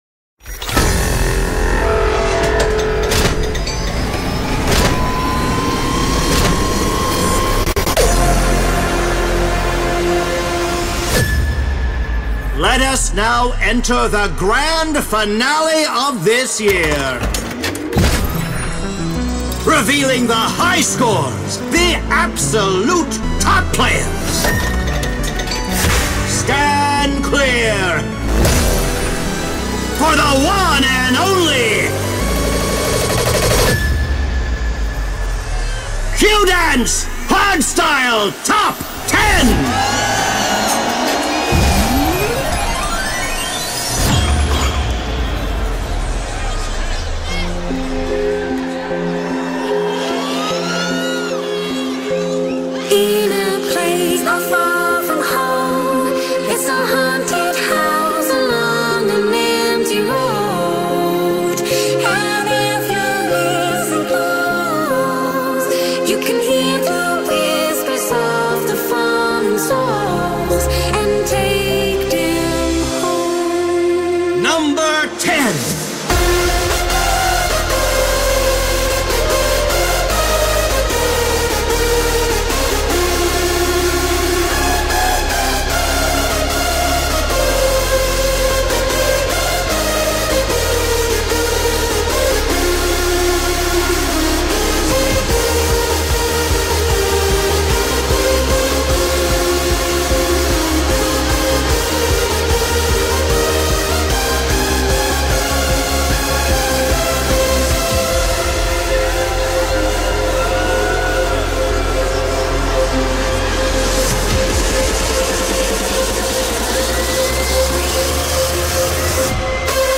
DJ Mixes and